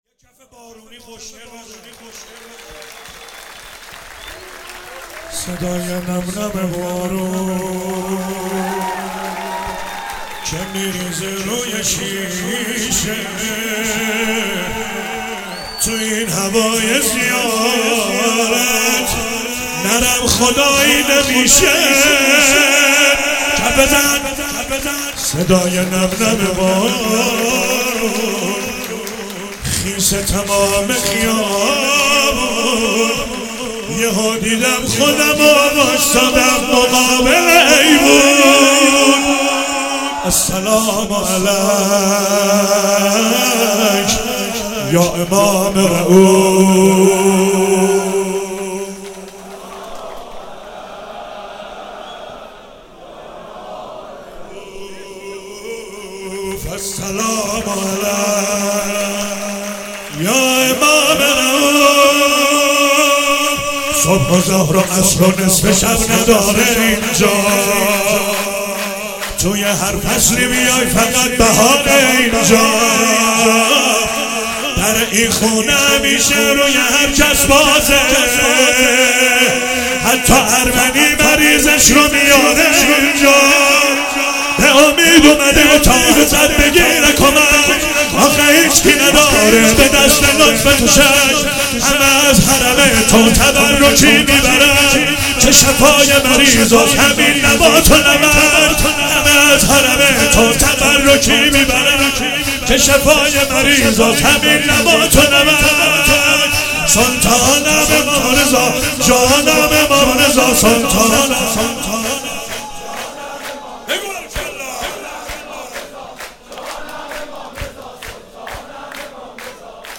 مراسم شب میلاد امام رضا(ع) 96
شور
شعرخوانی